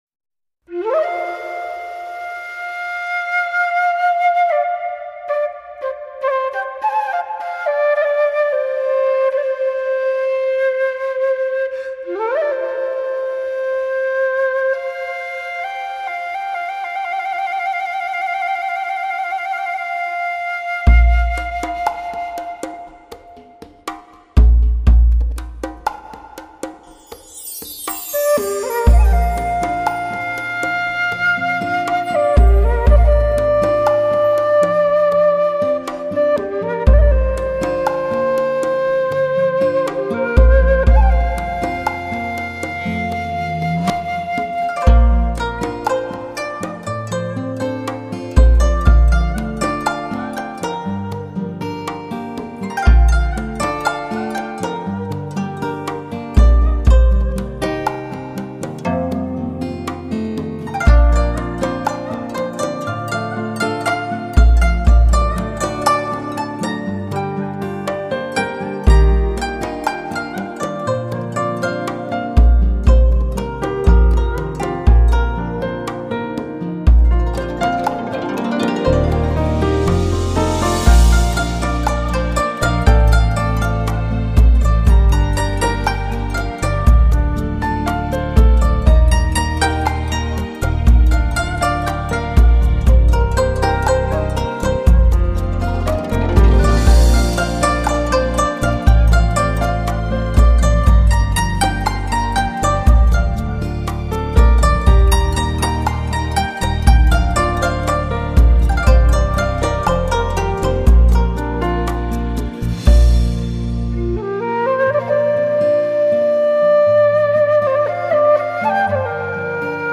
瑰丽绮美，空灵幽幻的中国风情美乐集